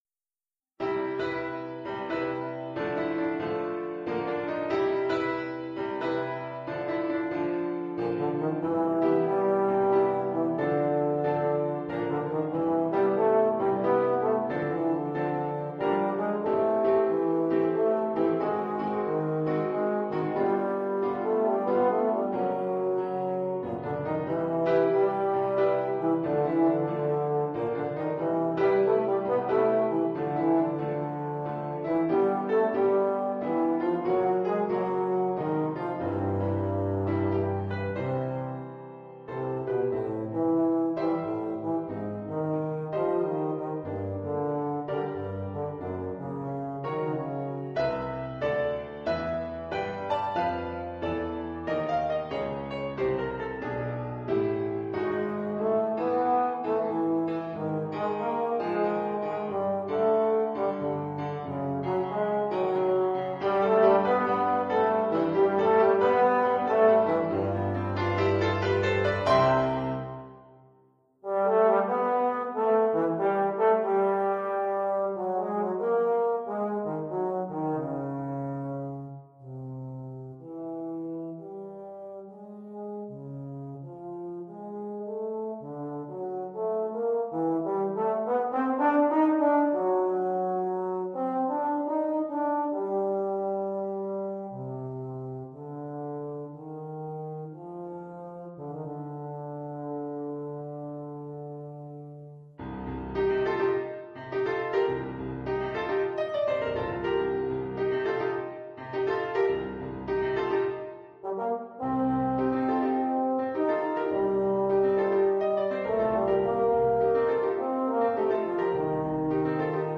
Formule instrumentale : Saxhorn basse/Tuba et piano
euphonium / tuba et piano.